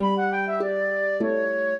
flute-harp
minuet14-5.wav